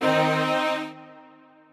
strings4_29.ogg